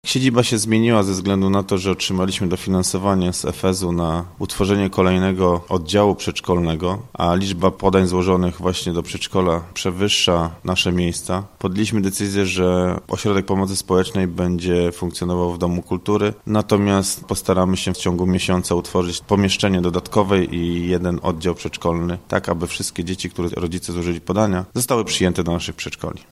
– W dotychczasowej siedzibie MOPS powstaje miejsce dla przedszkolaków – tłumaczy przenosiny Paweł Mierzwiak, burmistrz Nowogrodu Bobrzańskiego.